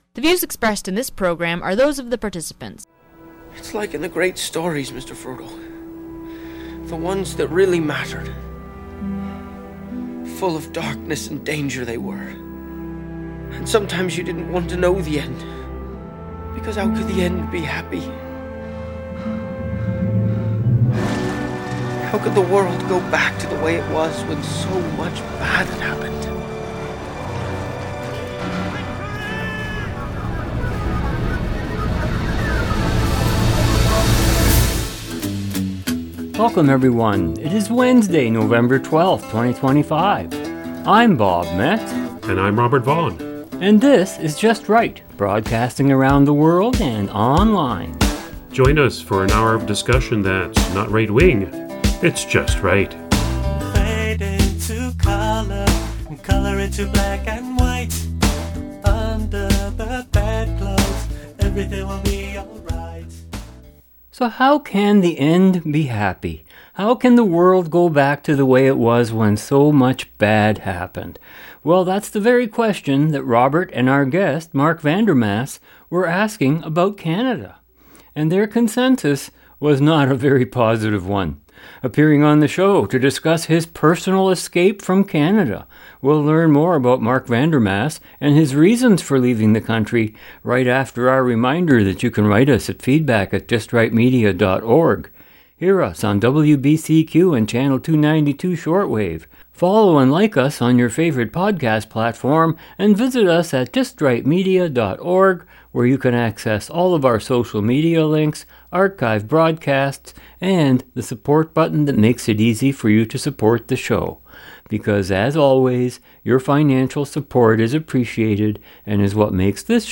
1 The Show With no Name 15/09/2025 10:30 1:00:03 Play Pause 3h ago 1:00:03 Play Pause Play later Play later Lists Like Liked 1:00:03 La radio divertida, informativa e interactiva.